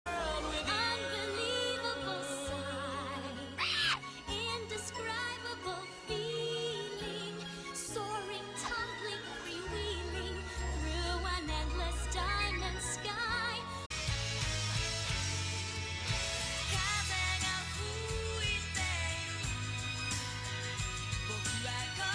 キーが半音違っているんですね。